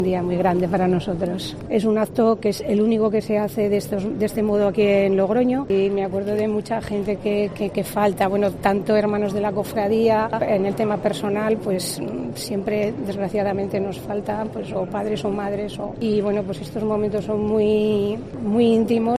relata emocionada la Limpieza